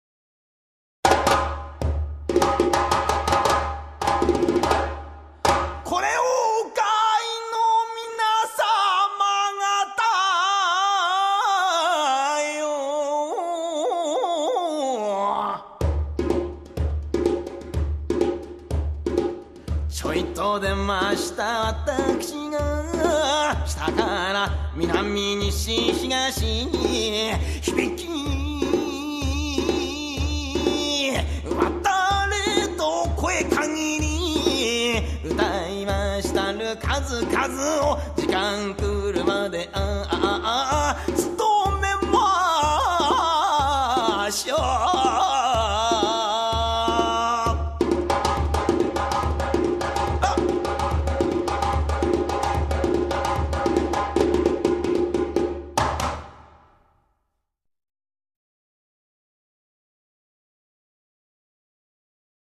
traditional drum beat with strong vocals
The instruments used were drums and vocals.